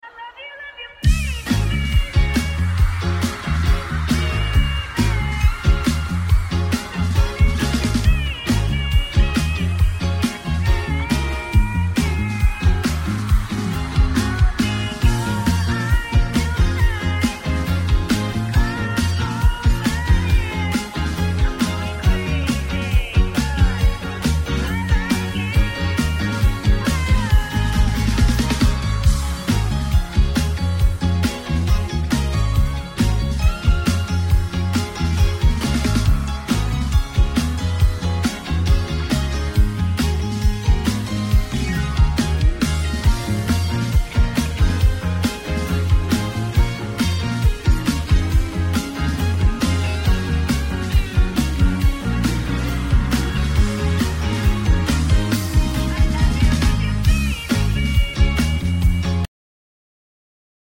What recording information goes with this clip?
intro and smoothest concert segue ever!